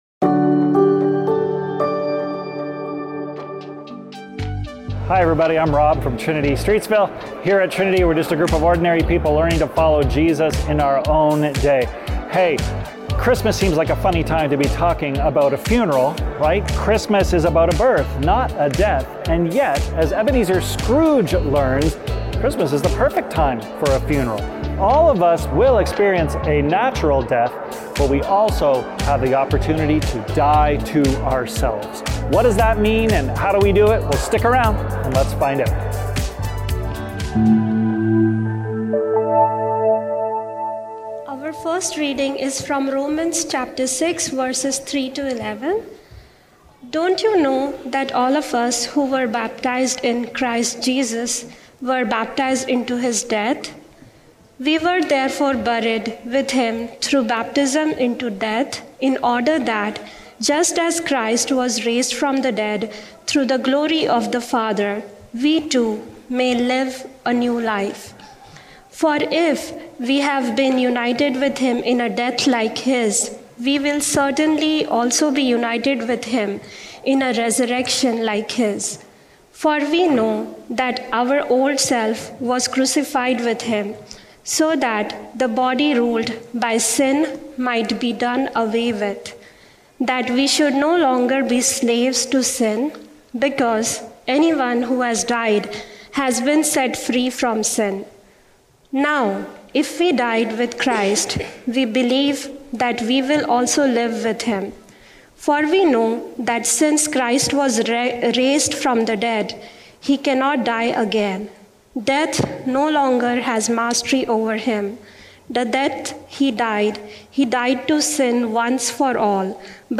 Trinity Streetsville - Grave Tidings | The Ghosts of Christmas | Trinity Sermons